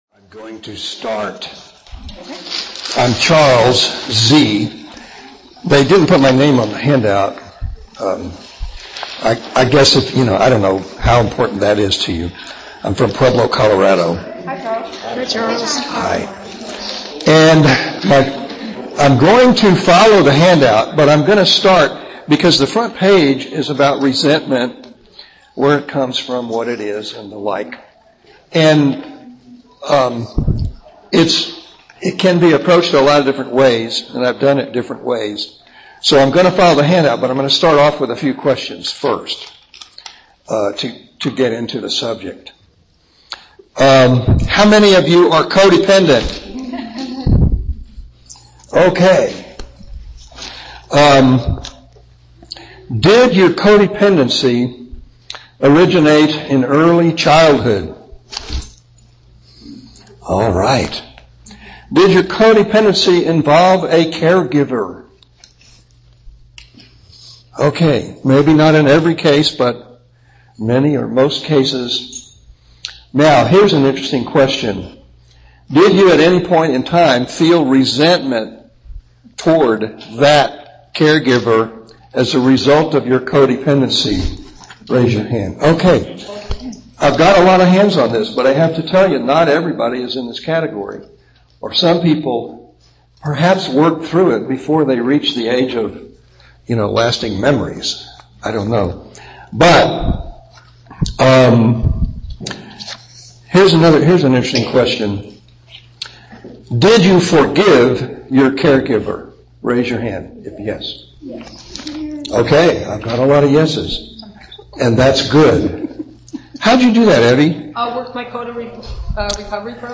Giving Up Resentments Using Forgiveness – Co-Dependents Anonymous (CoDA) Speakers